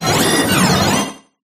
regieleki_ambient.ogg